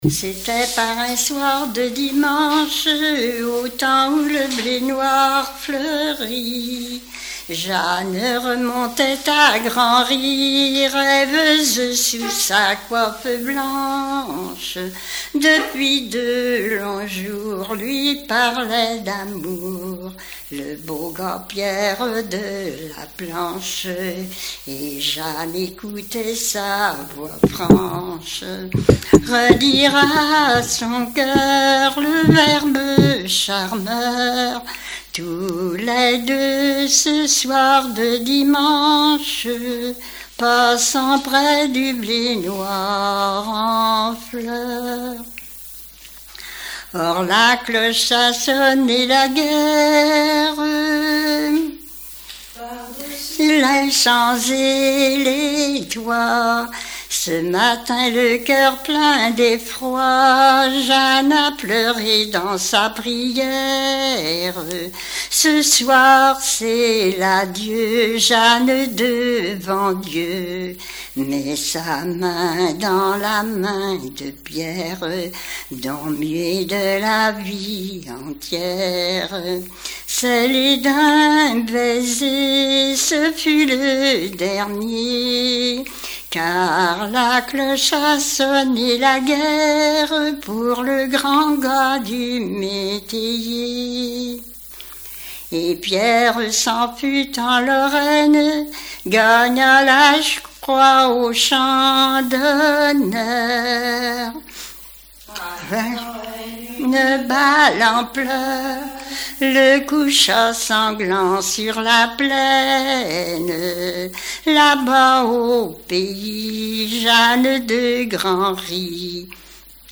Genre strophique
Répertoire de chansons populaires et traditionnelles
Pièce musicale inédite